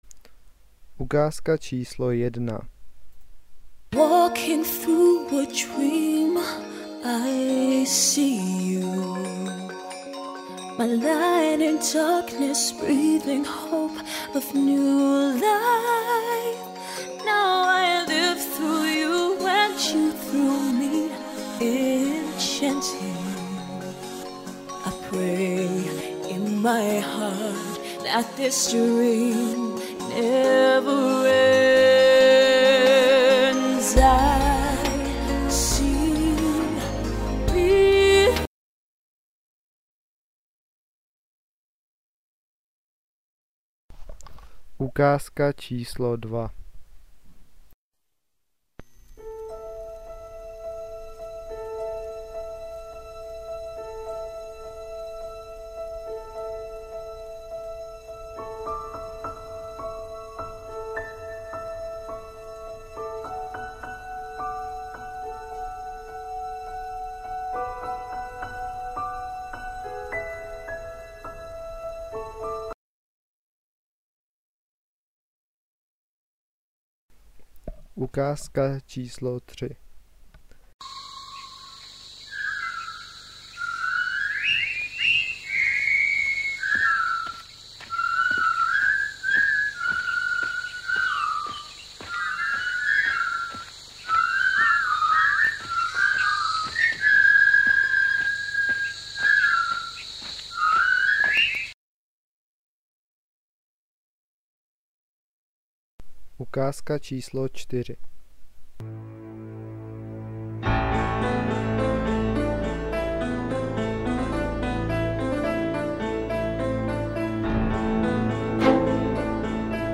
12 ukázek filmové hudby.